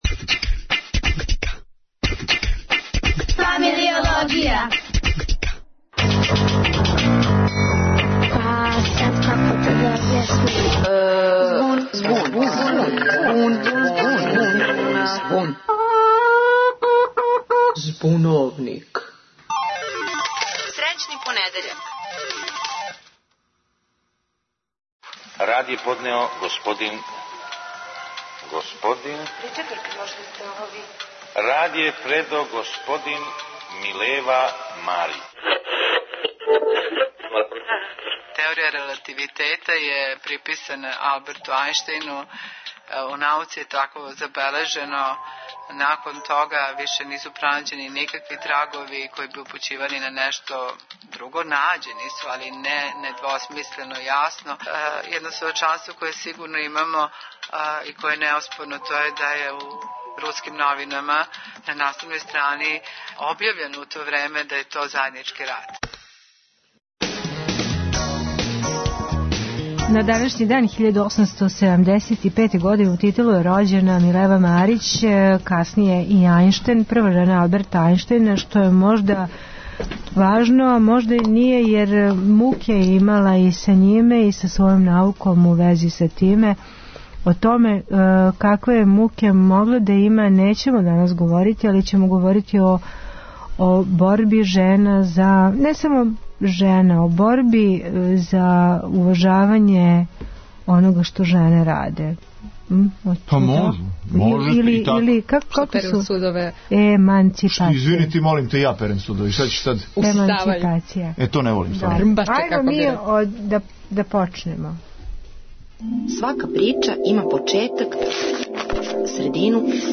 Говоре најмлађи, студенти, одрасли...